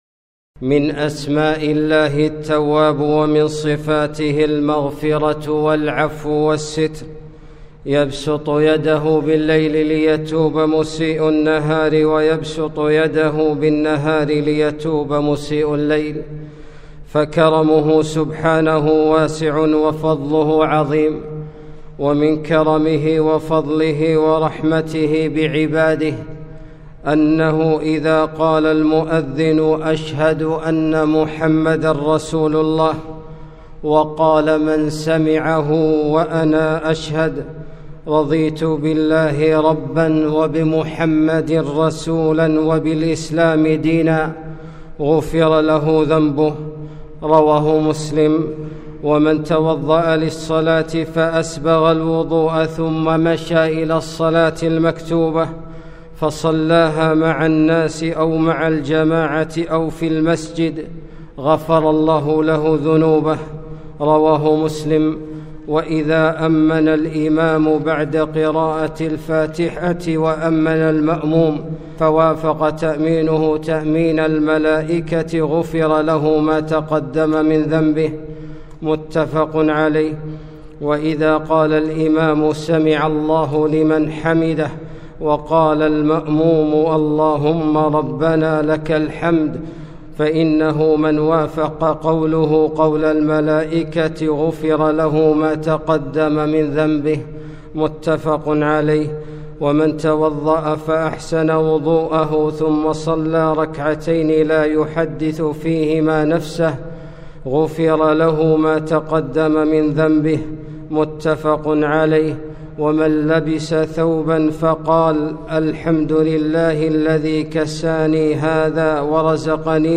خطبة - ما يفعلُ الله بعذابكم